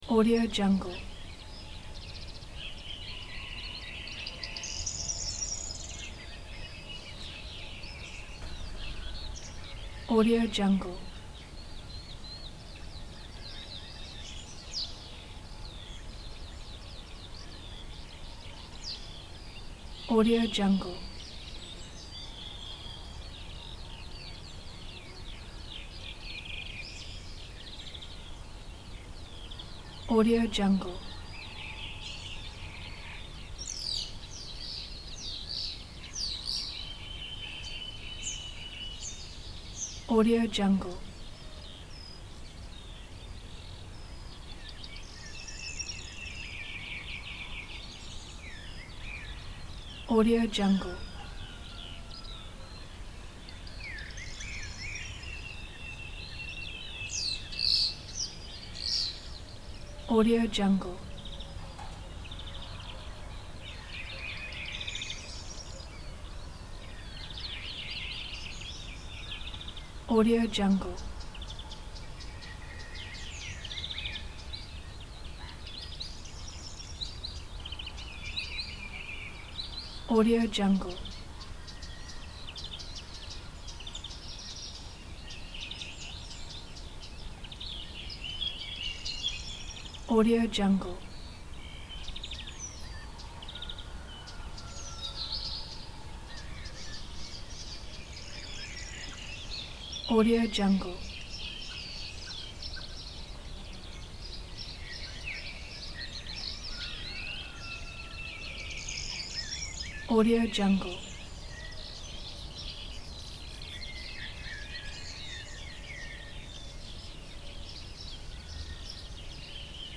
دانلود افکت صدای صبح روستا با صدای پرندگان 2
افکت صدای صبح روستا با صدای پرندگان 2 یک گزینه عالی برای هر پروژه ای است که به صداهای طبیعت و جنبه های دیگر مانند محیط، روستا و روستا نیاز دارد.
Sample rate 16-Bit Stereo, 44.1 kHz
Looped Yes